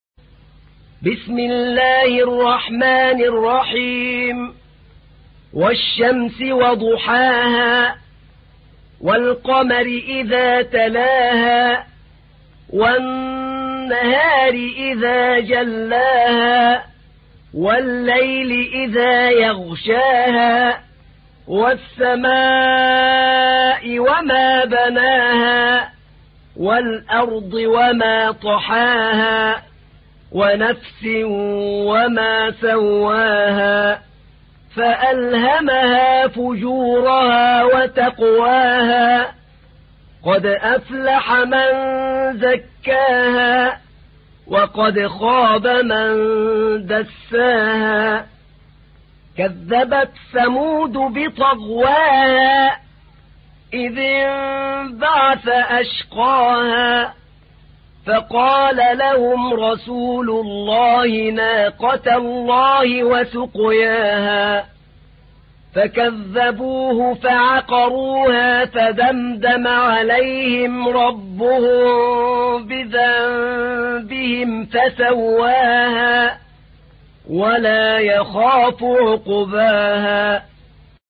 تحميل : 91. سورة الشمس / القارئ أحمد نعينع / القرآن الكريم / موقع يا حسين